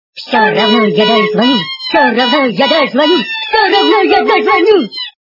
» Звуки » Смешные » Все равно я дозвонюсь! - Все равно я дозвонюсь!
При прослушивании Все равно я дозвонюсь! - Все равно я дозвонюсь! качество понижено и присутствуют гудки.